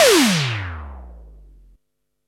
SIMMONS SDS7 12.wav